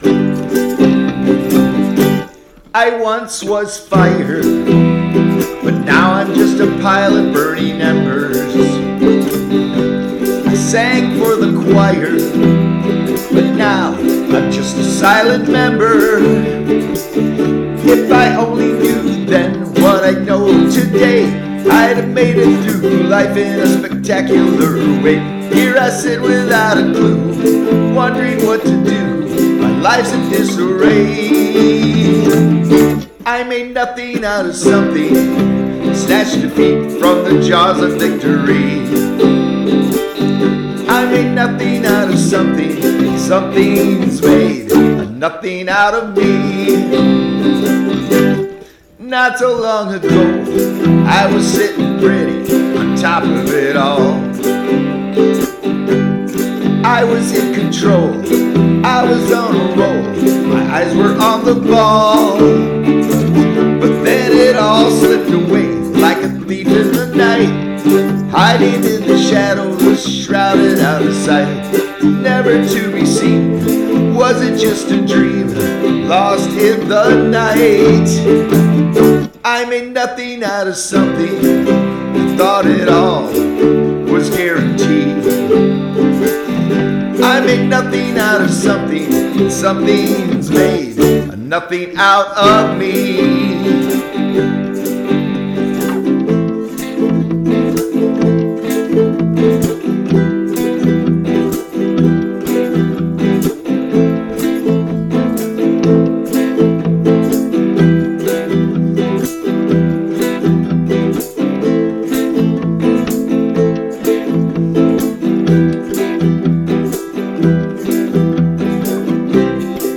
I recorded the original six tracks with my Audigo and mixed it in Audacity.